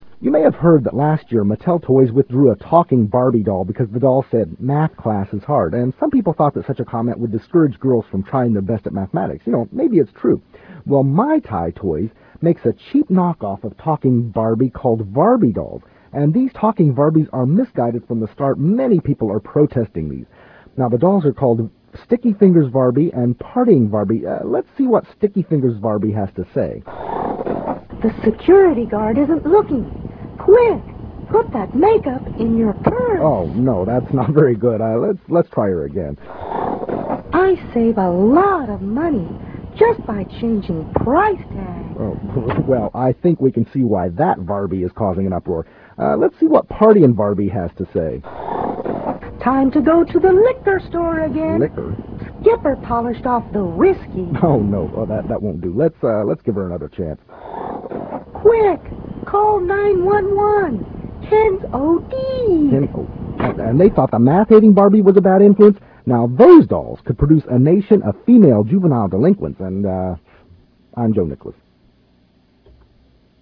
I am all voices.
The sound quality is very good considering how much it scrunches the files.
Most of those examples were captured on normal bias analog tape.